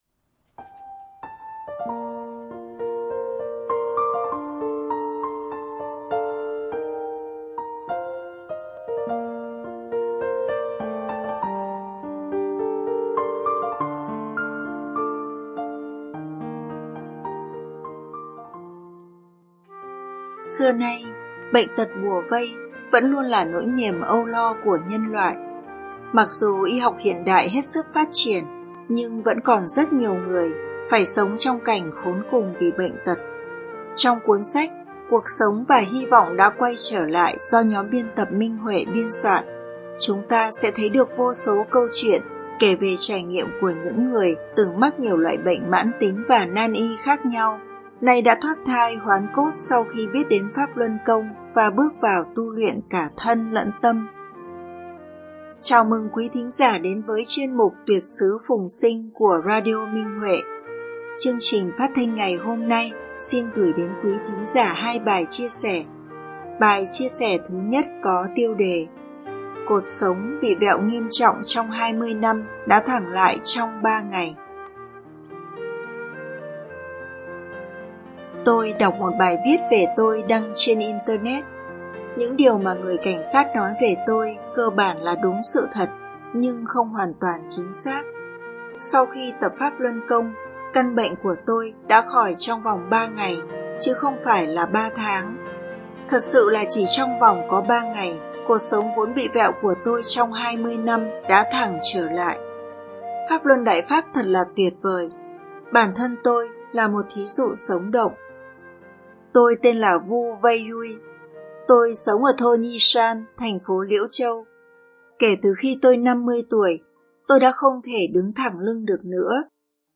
Chương trình phát thanh ngày hôm nay xin gửi đến quý thính giả hai bài chia sẻ có tiêu đề sau đây: